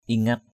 /i-ŋa:t/